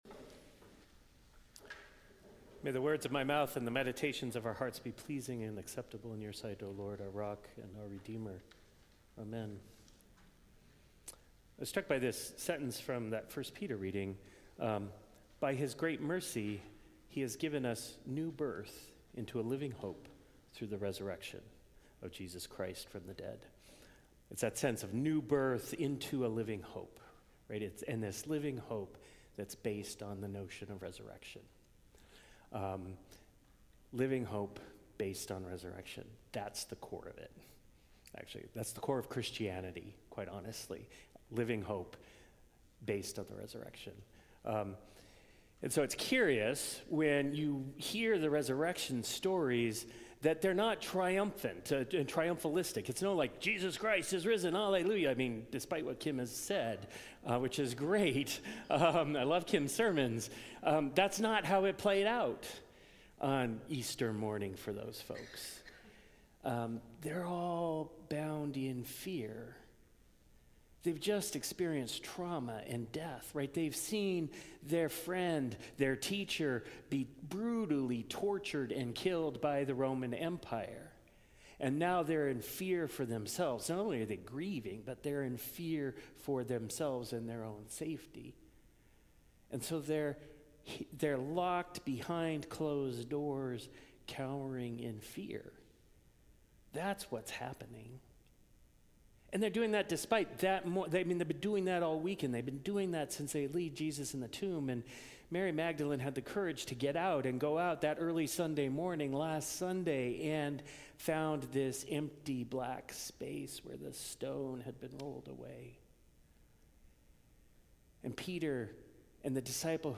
Sermons | Grace Episcopal Church